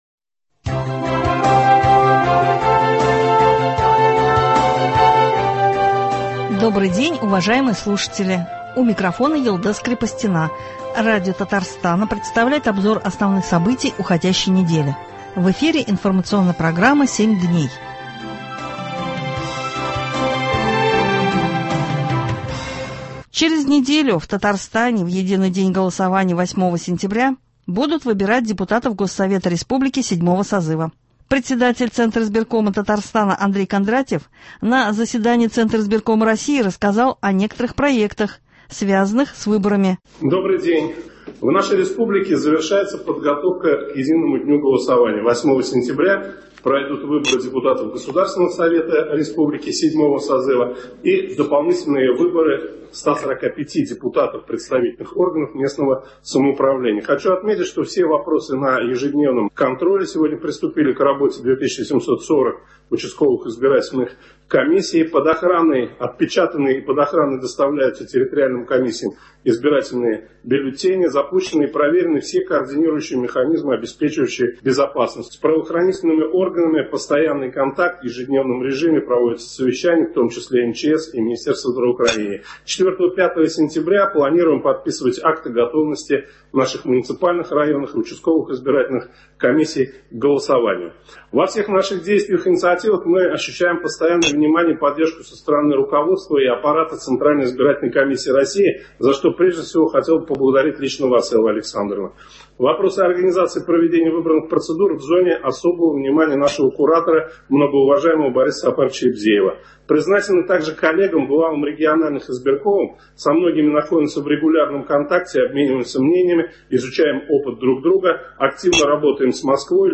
Обзор событий недели.